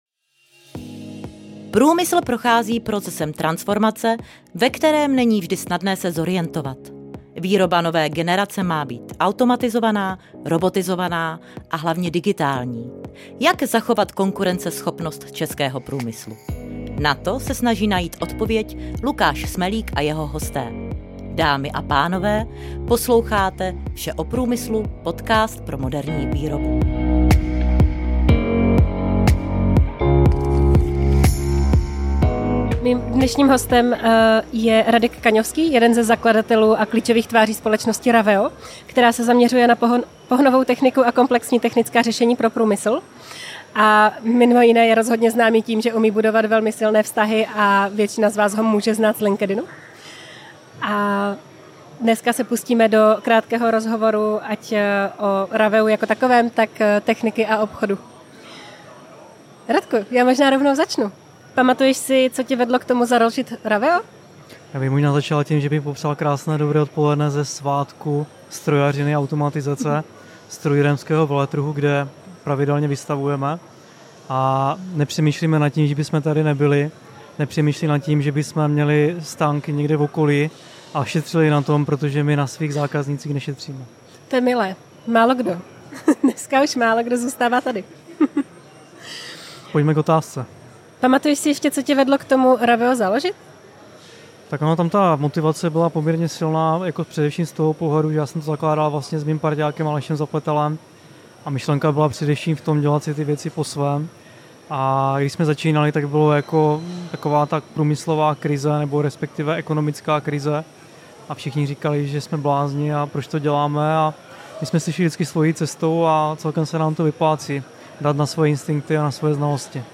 V rozhovoru probereme společnost Raveo jako takovou, ale také techniku nebo obchod. Série je speciál ke konferenci ROBOTY, která každý rok mapuje trendy průmyslové robotizace.